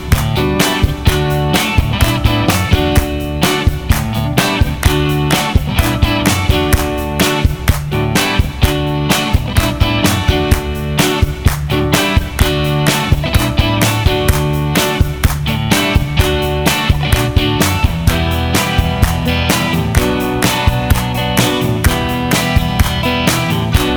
No Backing Vocals Soul / Motown 2:46 Buy £1.50